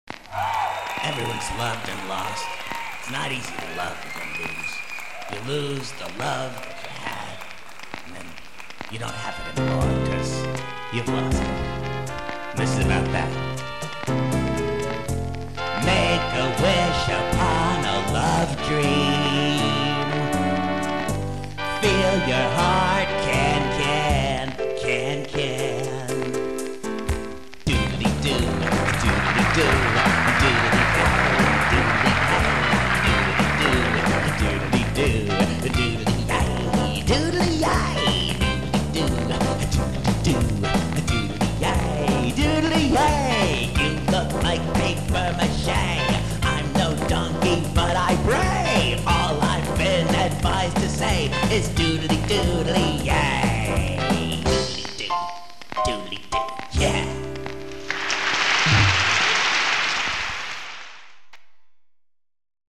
" a tender ballad